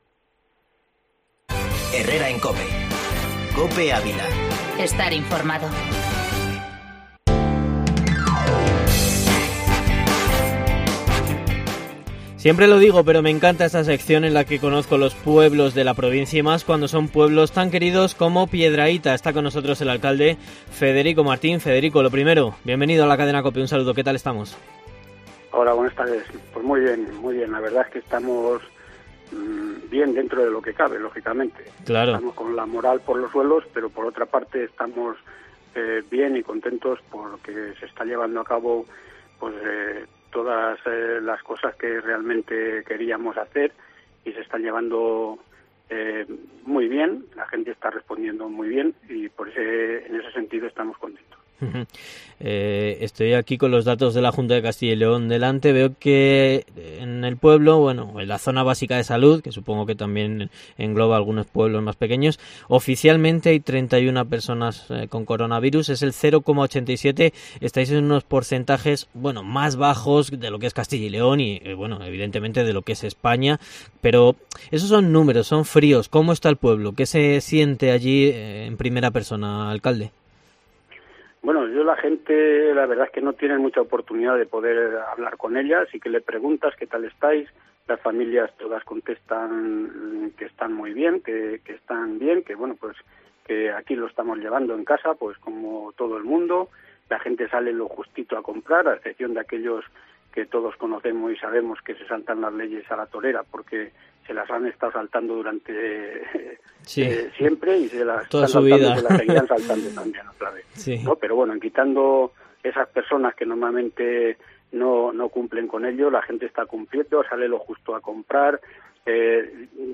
Entrevista al alcalde de Piedrahita, Federico Martín, en COPE Ávila